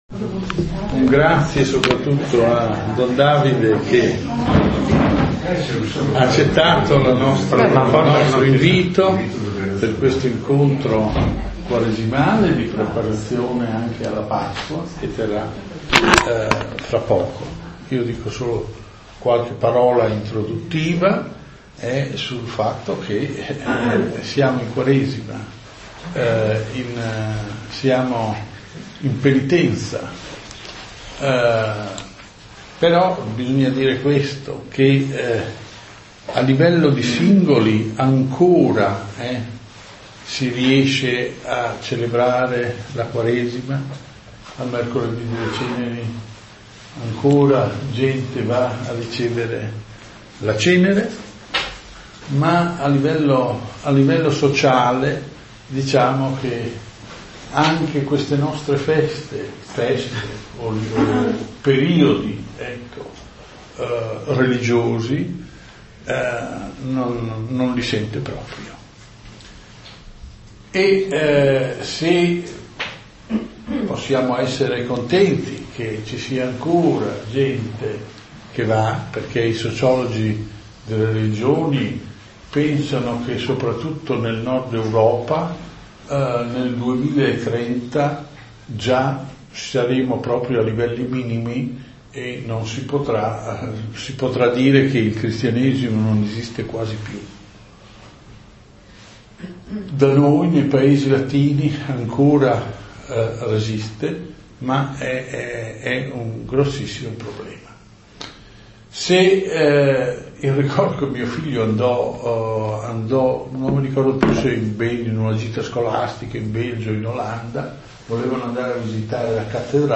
Catechesi pasquale — 29 marzo 2023